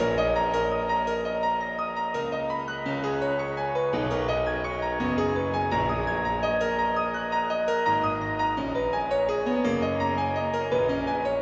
描述：果味循环
Tag: 84 bpm Rap Loops Drum Loops 492.42 KB wav Key : Unknown